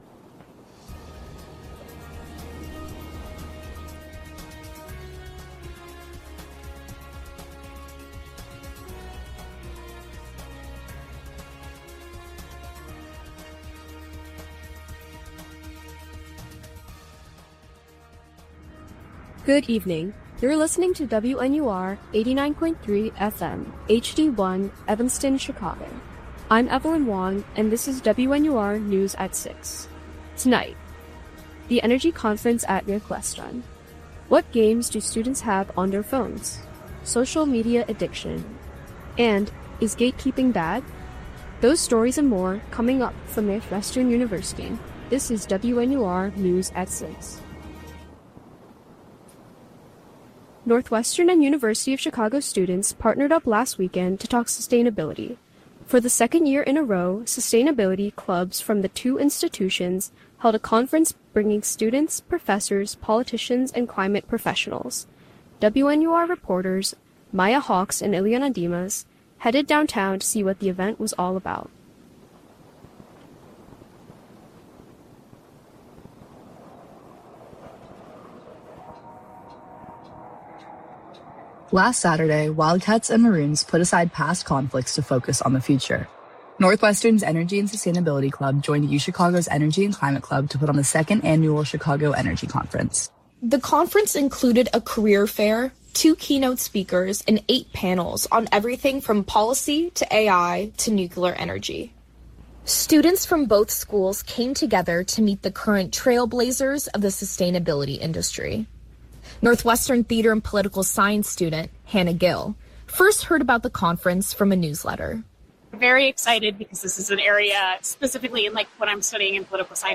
April 17, 2026: Energy conference at Northwestern, what games do students have on their phones, social media addiction, and is gatekeeping bad? WNUR News broadcasts live at 6 pm CST on Mondays, Wednesdays, and Fridays on WNUR 89.3 FM.